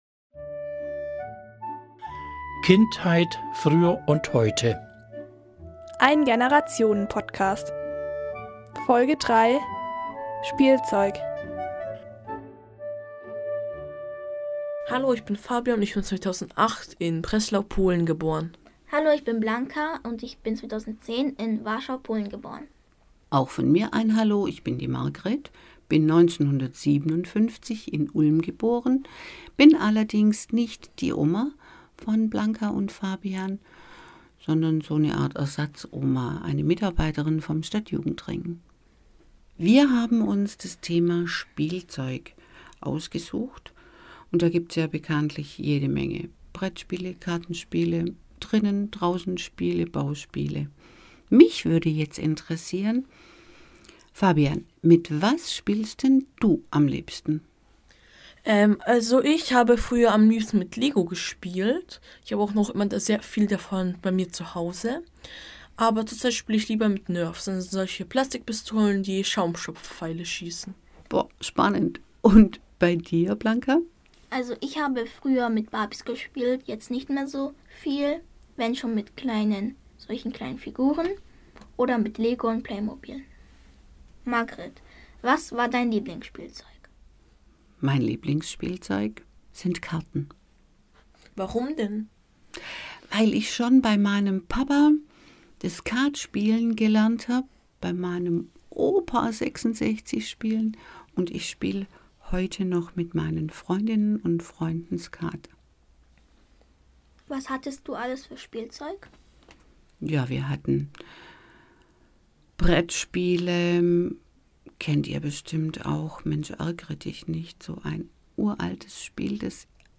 Ulmer Radio von Kindern für Kinder